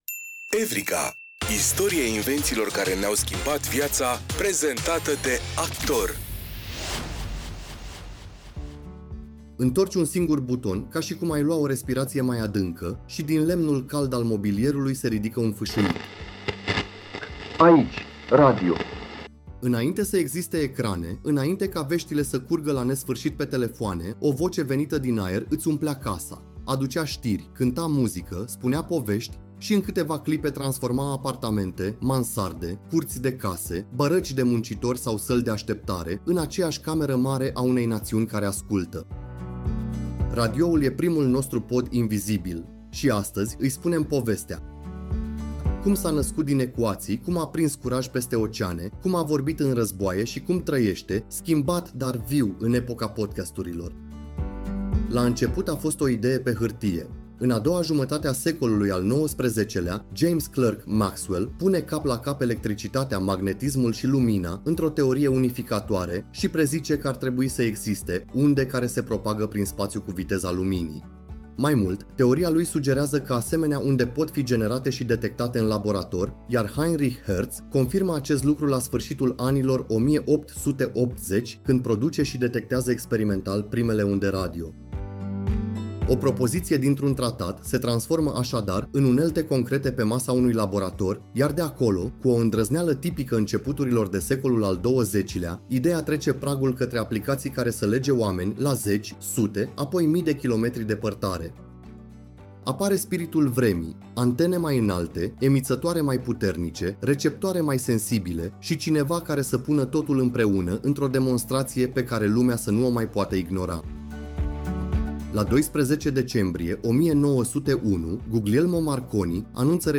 În fiecare episod, descoperim poveștile oamenilor și ideilor care au schimbat felul în care trăim, comunicăm și gândim. Cu un ton cald și curios, „Evrika” transformă știința și tehnologia în aventuri umane, pe înțelesul tuturor. Producția este realizată cu ajutorul inteligenței artificiale, combinând cercetarea documentară cu narațiunea generată și editată creativ de echipa SOUNDIS România.